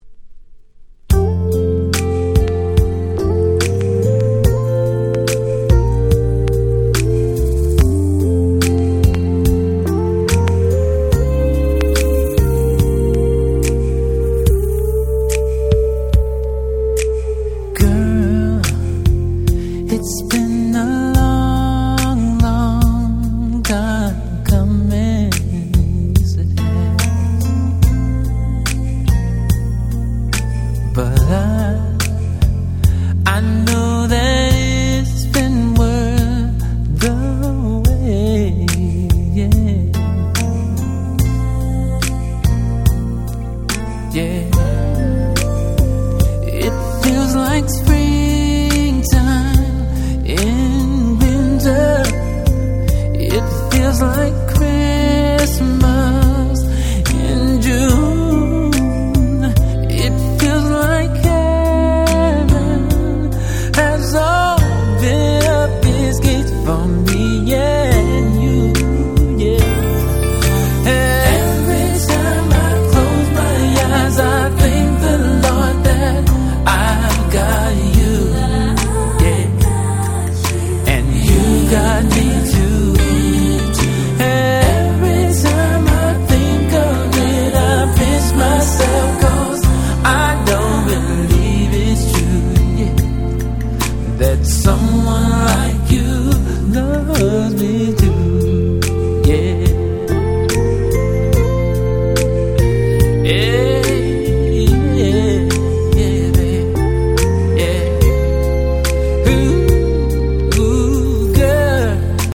97' Super Hit R&B / Slow Jam !!
問答無用に最高のスロウ！！